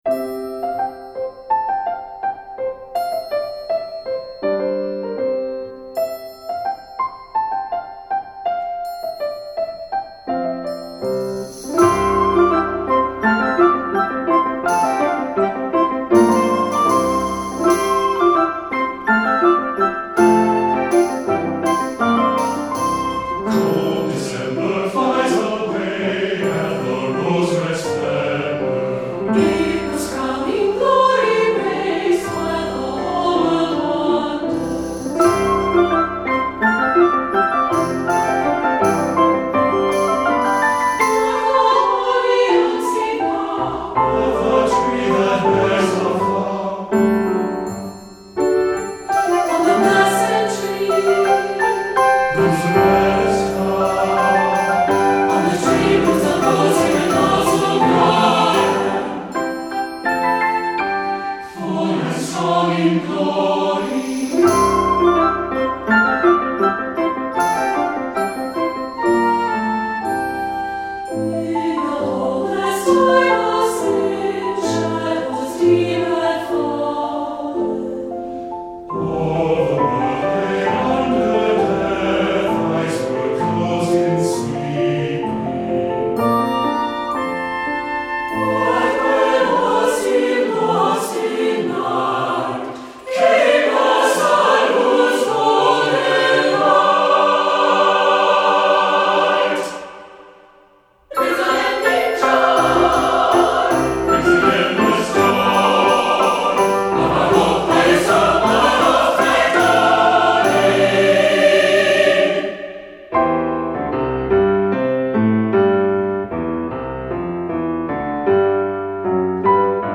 Voicing: SATB and 4 Hand Piano